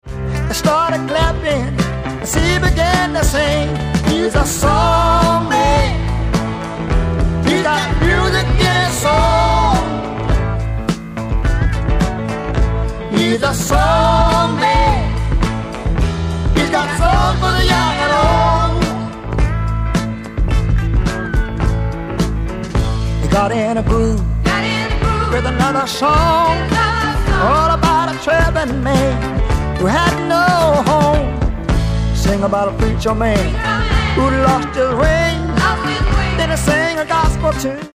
ソリッドなリズム・セクションとリズミカルなホーンをフィーチャーしたスワンプ・ロック傑作
ブルー・アイド・ソウル感溢れるふたりのヴォーカルのコンビネーションも最高の一枚！